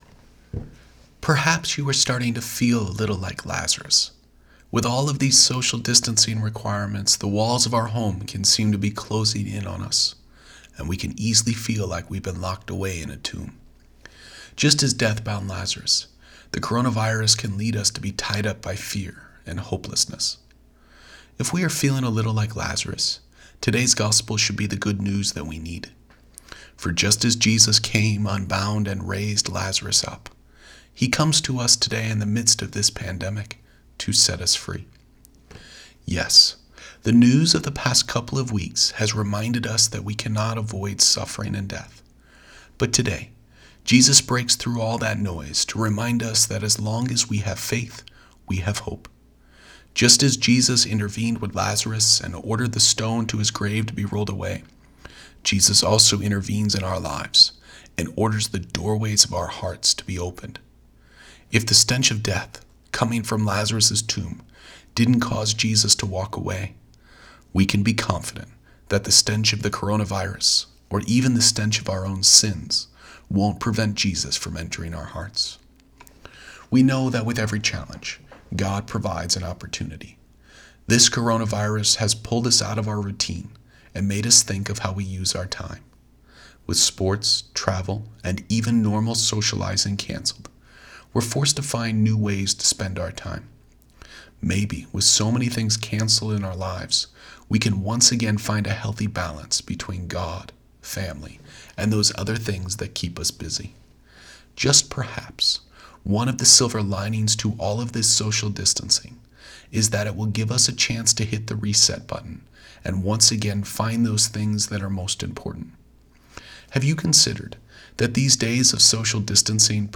Posted in Homily